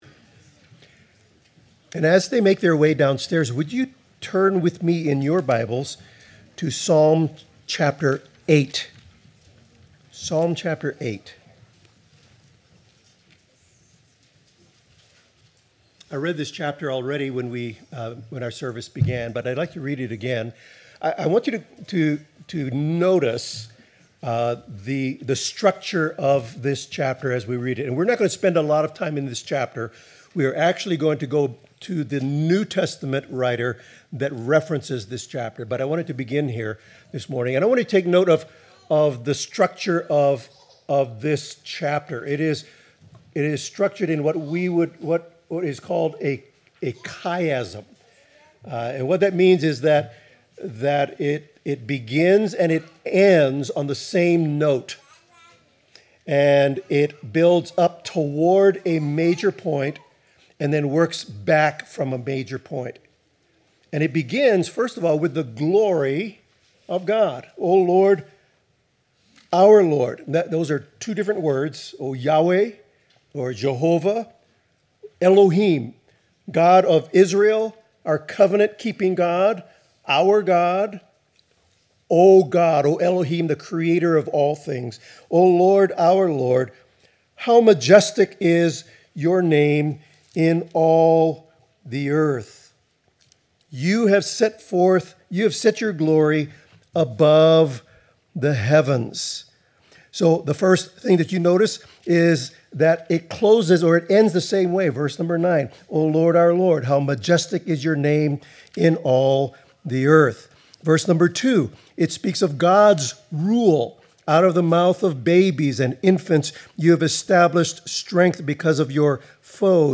Hebrews 2:5-18 Service Type: Morning Service God’s majesty and glory are revealed in Christ’s deliverance of His people.